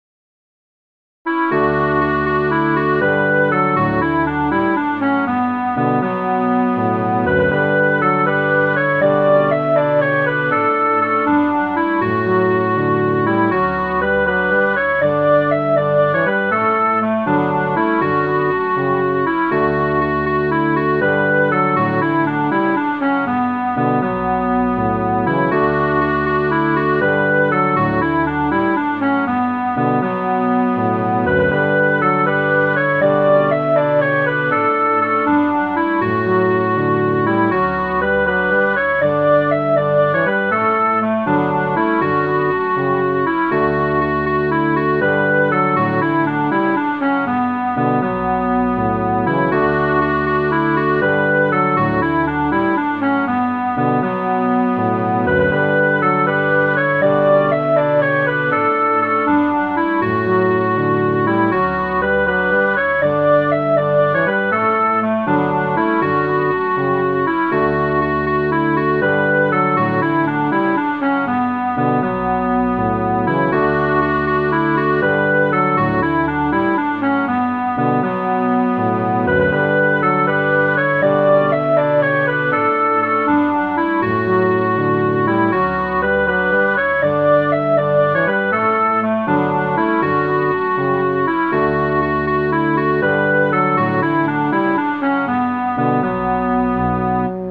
plough.mid.ogg